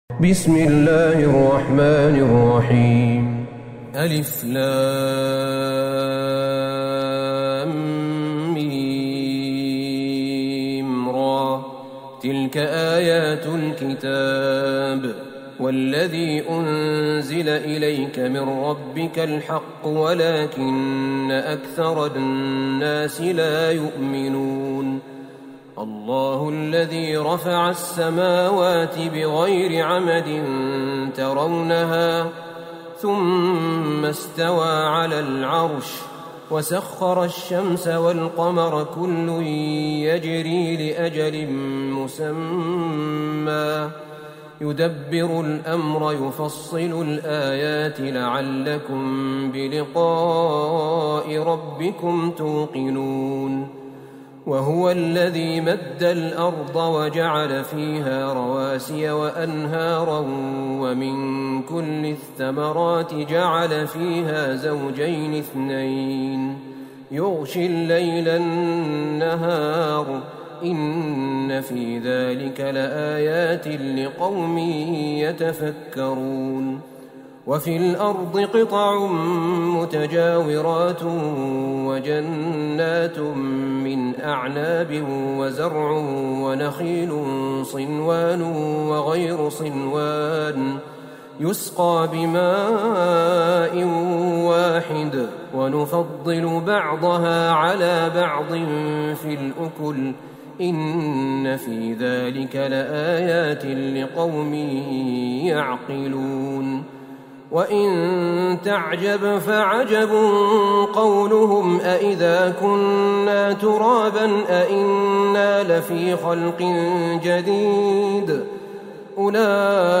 سورة الرعد Surat ArRa'd > مصحف الشيخ أحمد بن طالب بن حميد من الحرم النبوي > المصحف - تلاوات الحرمين